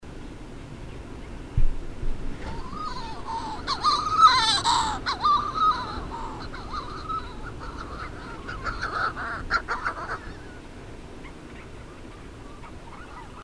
Manx Shearwater (Puffinus puffinus)
Comments: Males and females distinguishable by voice (see below).
Play MP3  Male followed by female in flight. Middle Lawn Island, July 2000.